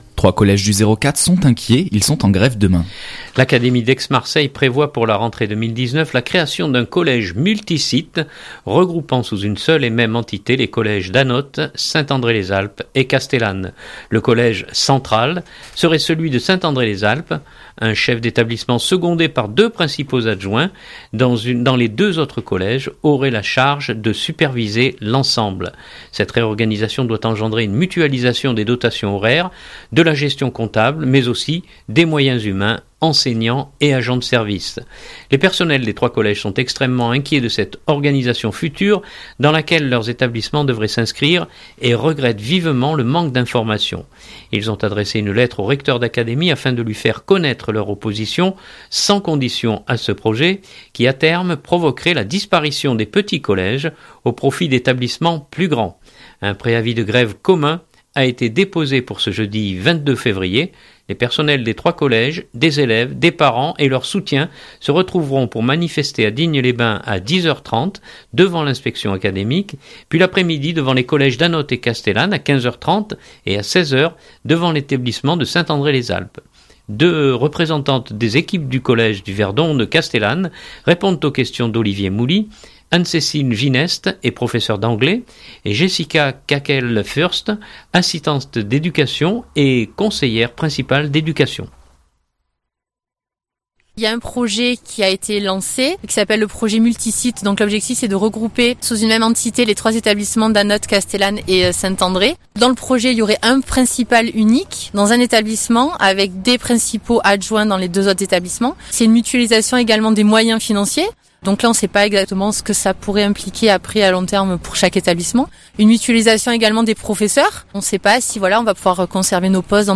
Journal du 2018-02-21 Castellane-Collège.mp3 (5.89 Mo)